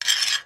Index of /files/sounds/material/metal/scrape/
scrape02.mp3